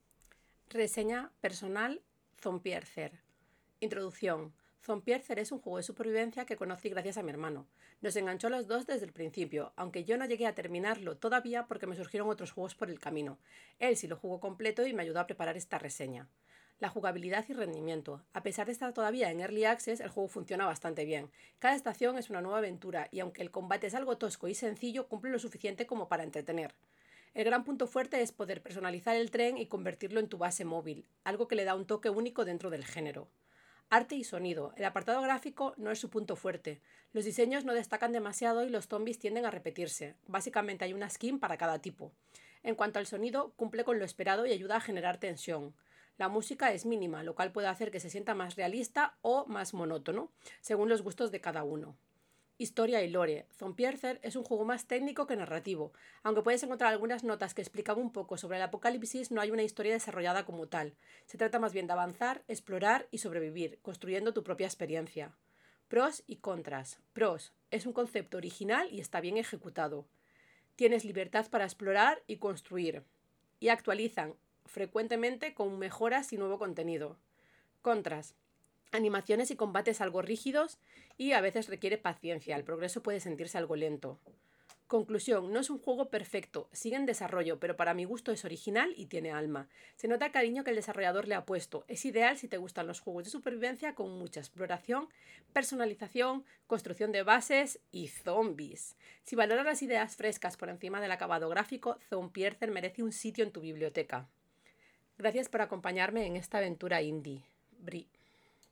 Reseña personal en audio: